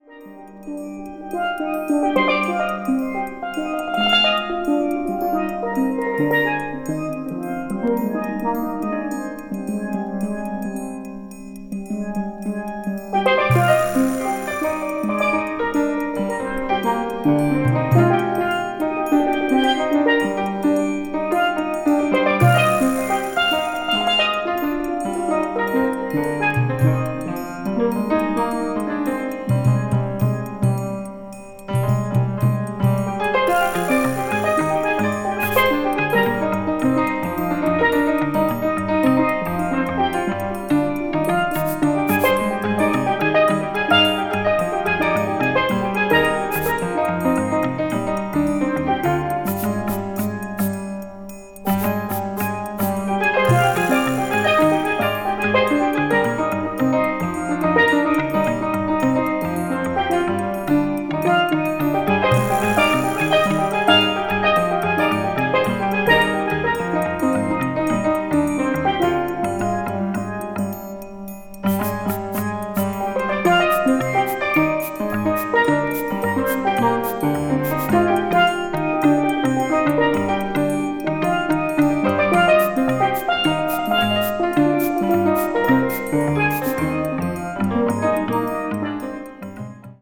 アーバン・バレアリックなフィーリングが気持ちイイA2/B3、涼しげでコンテンポラリーなB2がなかでも大推薦。
balearic   crossover   fusion   jazz groove   tropical